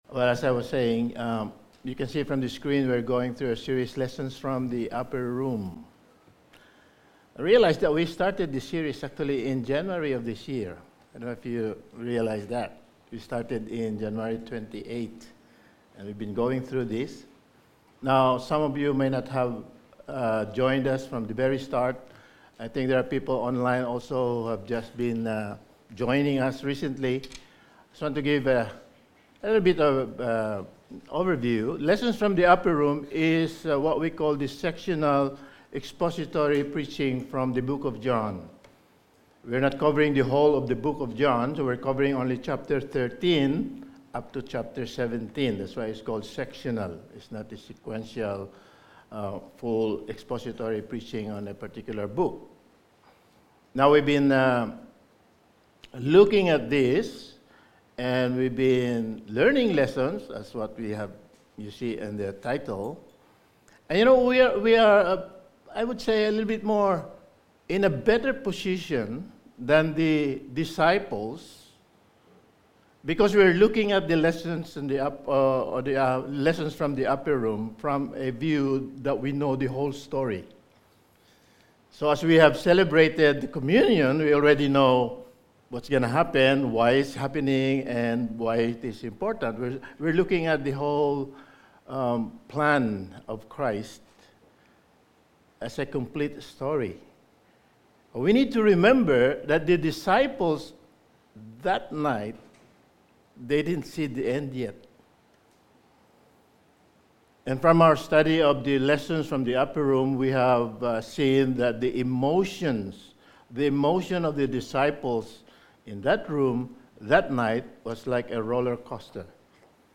Lessons From the Upper Room Series – Sermon 9: Hated But Helped
Service Type: Sunday Morning